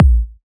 edm-kick-04.wav